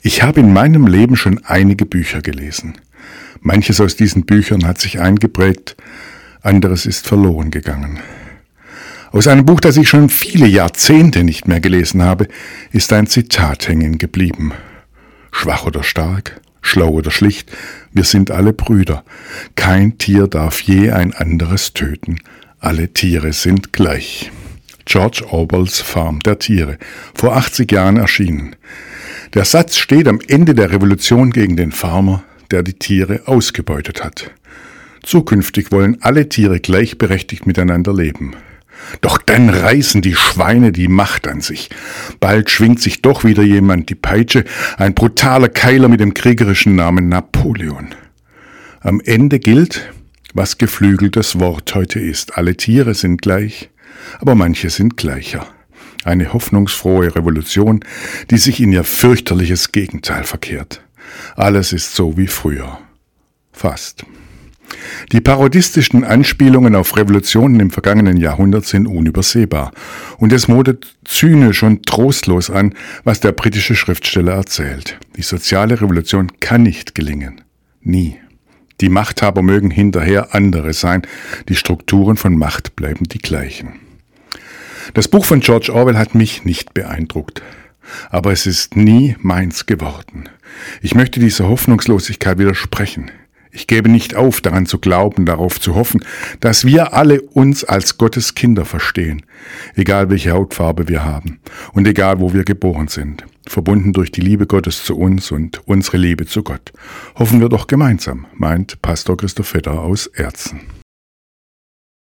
Radioandacht vom 26. August